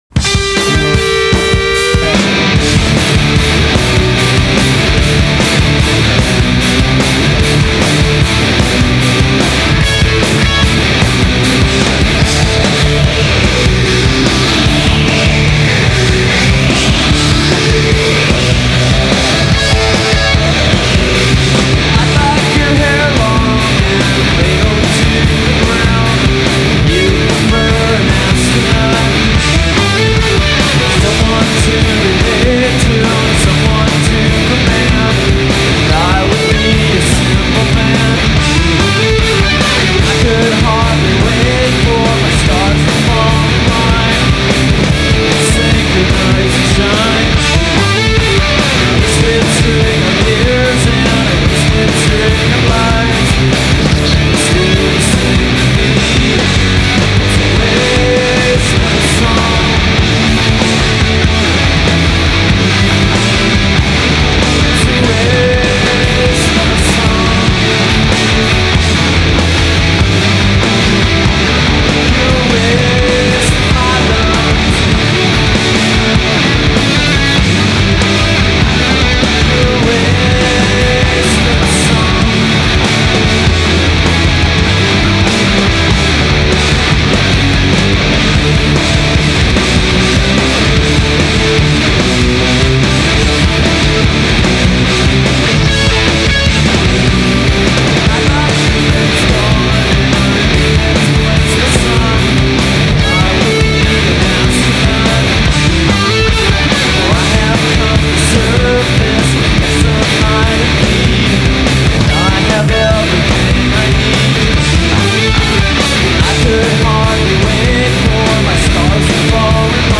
Hair Long" (Live)